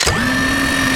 gatling_wind_up.wav